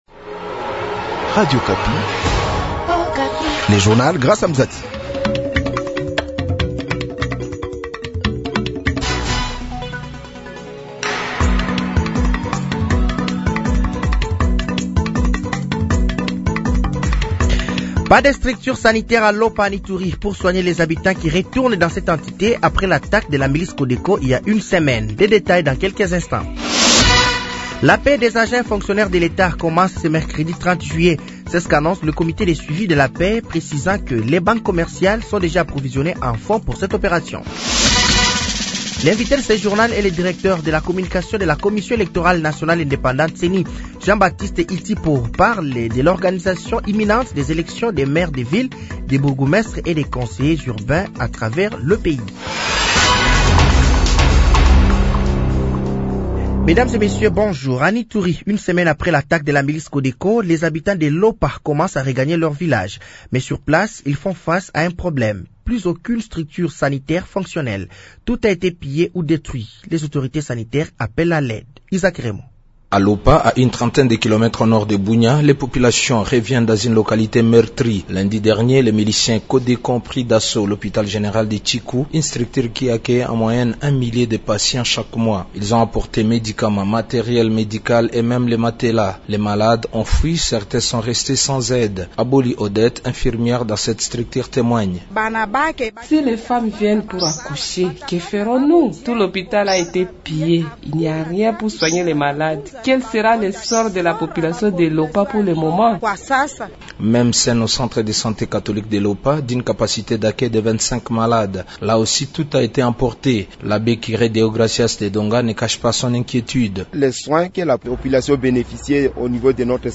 Journal français de 8h de ce mercredi 30 juillet 2025